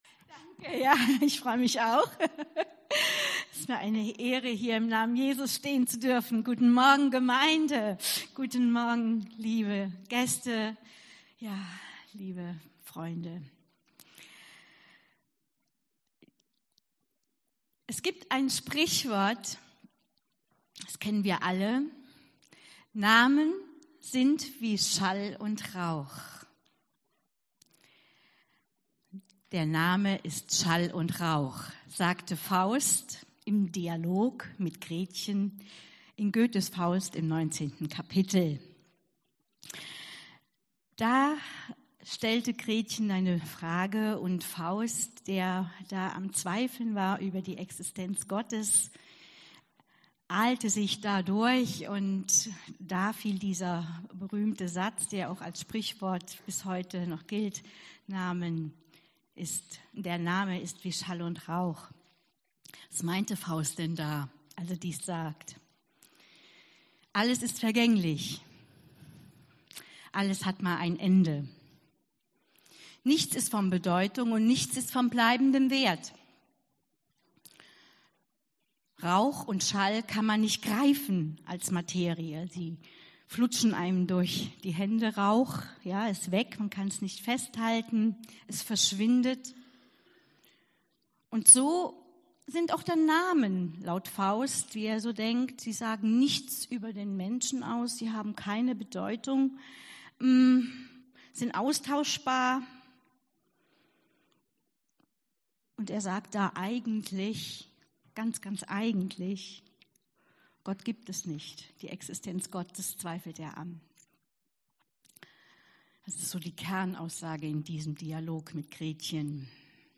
Predigt 28.12.2019